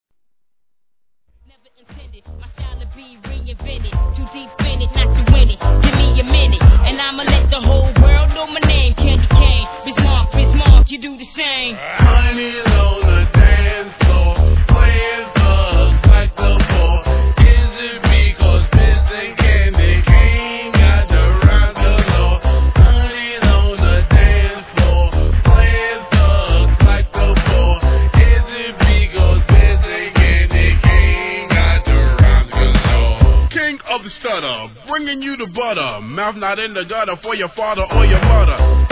HIP HOP/REGGARE/SOUL/FUNK/HOUSE/